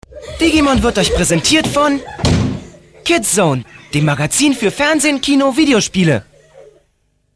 Hier mal eine kleine Auswahl an Stimmproben: